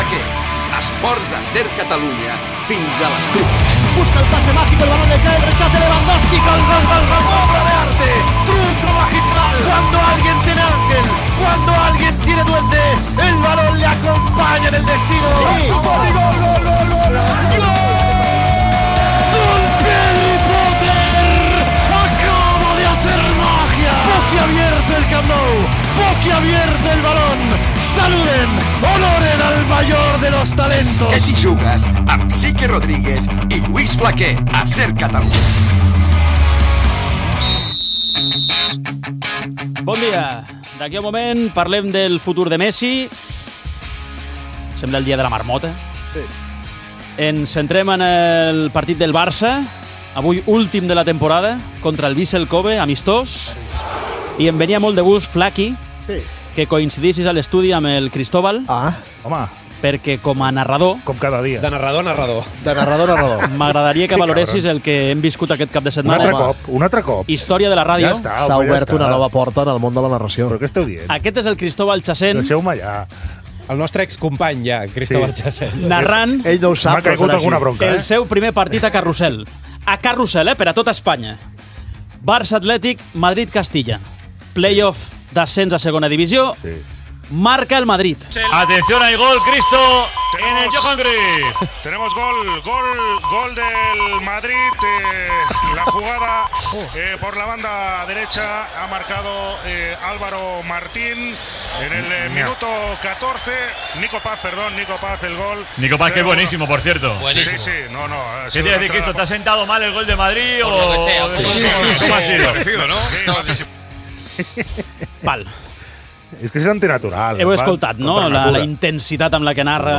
54bc9de47f816a2d1d6760ec4e7a06ddfbc9d88c.mp3 Títol SER Catalunya Emissora SER Catalunya Cadena SER Titularitat Privada estatal Nom programa Què t'hi jugues! Descripció Careta del programa, sumari
Gènere radiofònic Esportiu